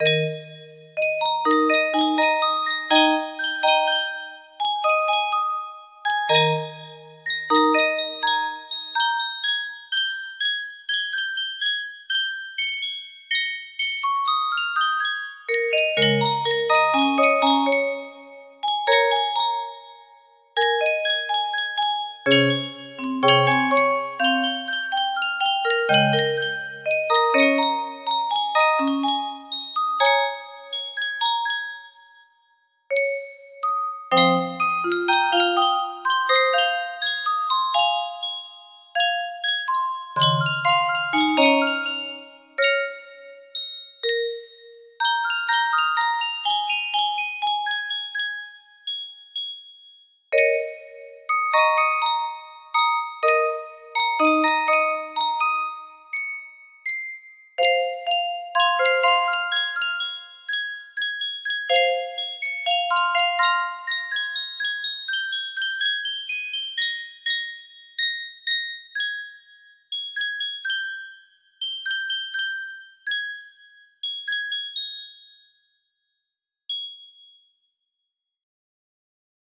Ralph music box melody
Full range 60